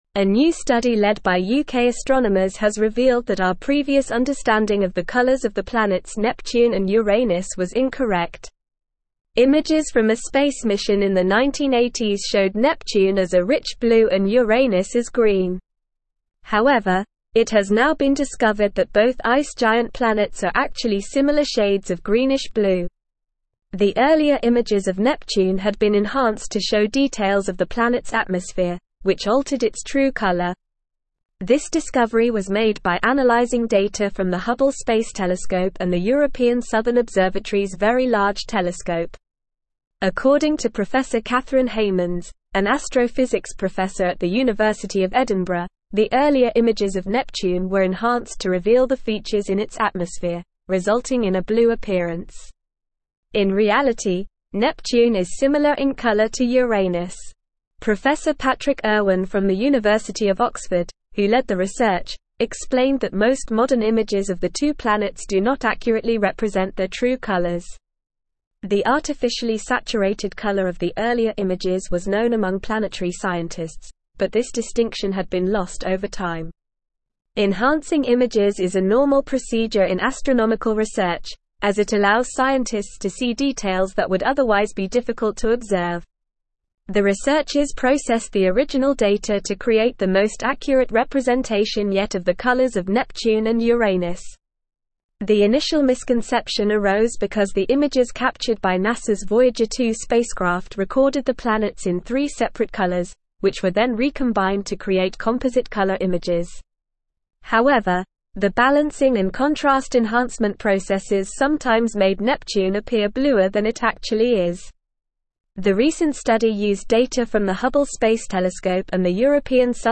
Normal
English-Newsroom-Advanced-NORMAL-Reading-True-Colors-of-Neptune-and-Uranus-Revealed.mp3